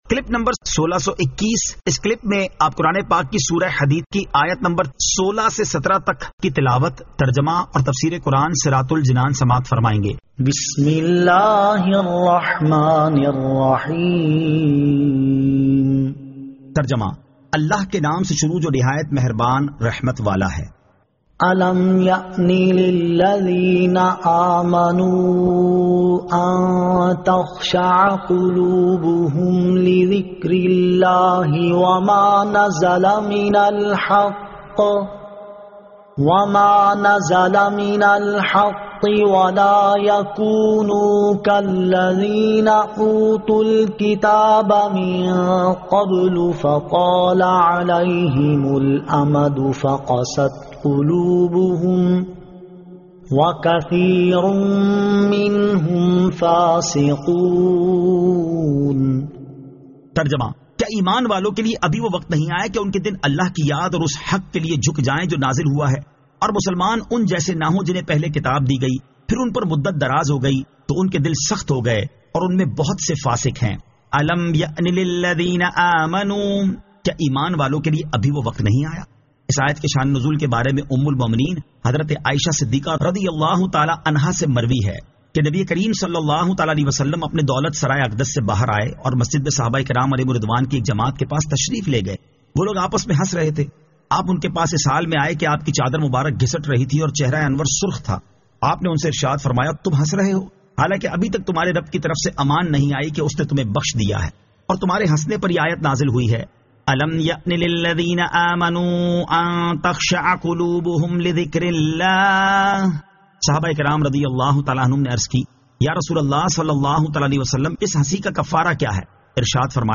Surah Al-Hadid 16 To 17 Tilawat , Tarjama , Tafseer